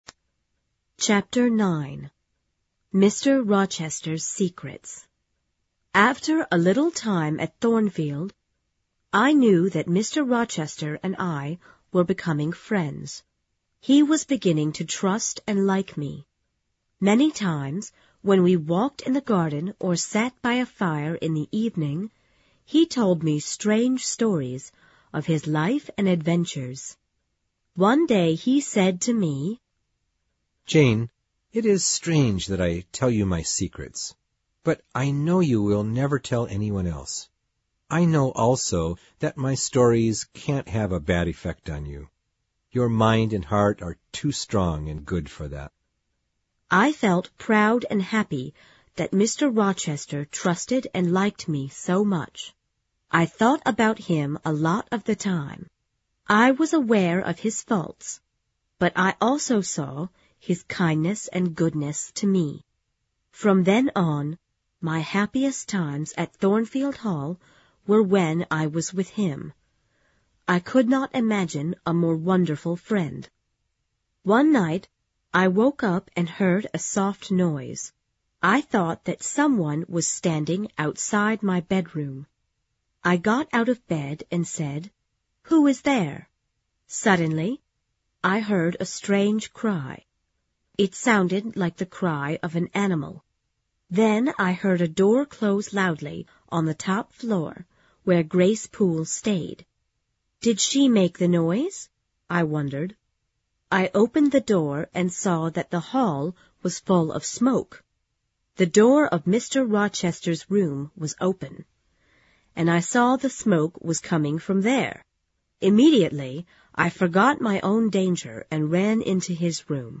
有声名著之简爱Jene Eyer Chapter9 听力文件下载—在线英语听力室